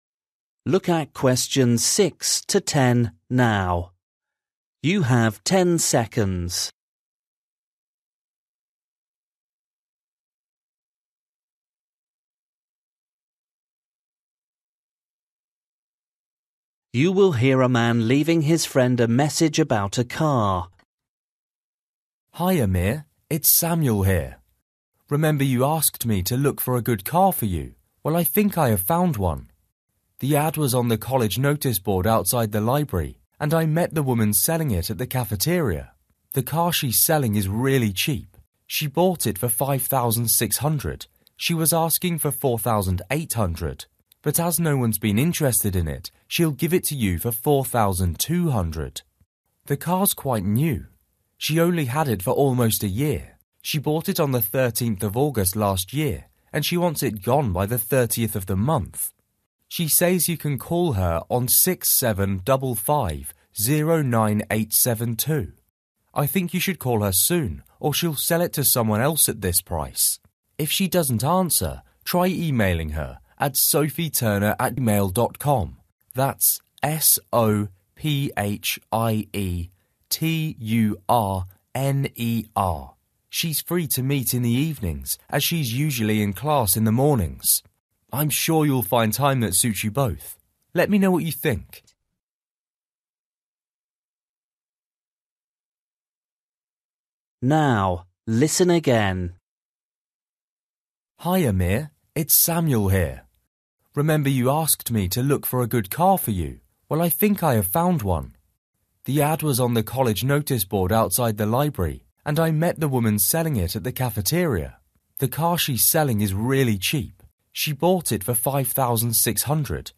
You will hear a man leaving his friend a message about a car.